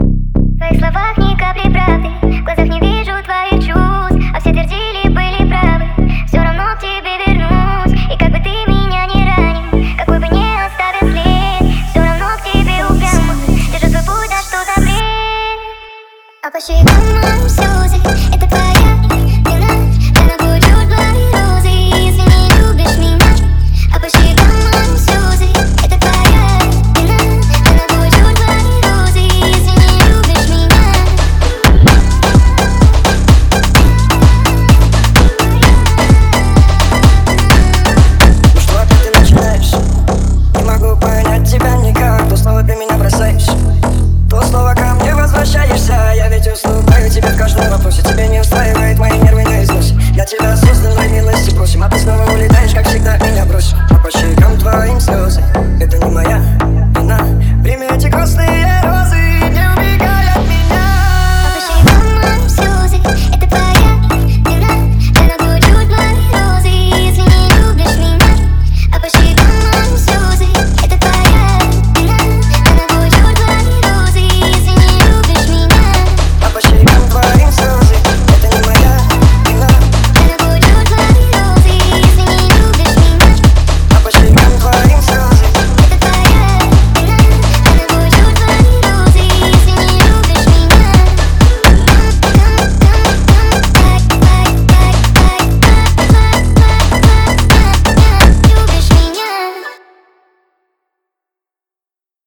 phonk